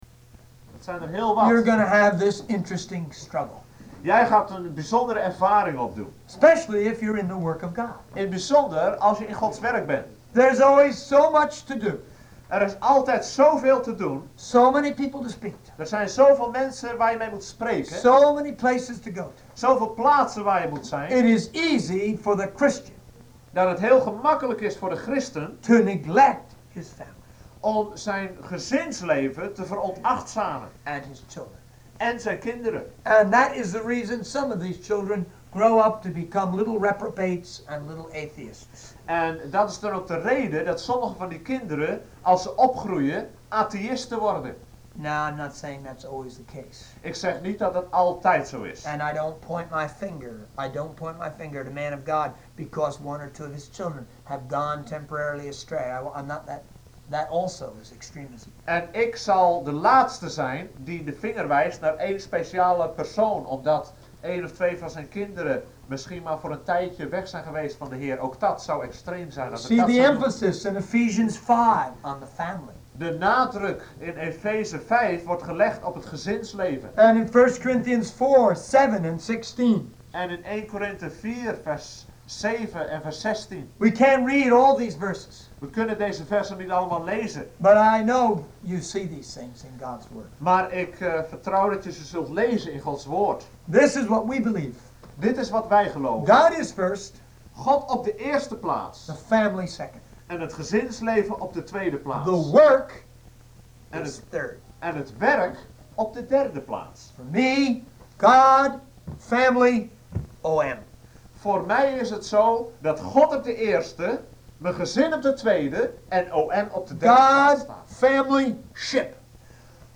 Evenwichtigheid (Eng - Dutch)